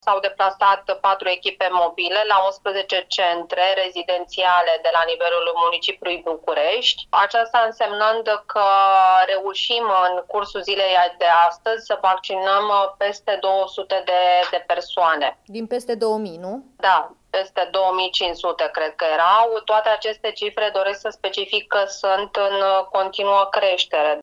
Directorul general al DSP Bucuresti, Oana Nicolescu, într-o declarație pentru Europa FM: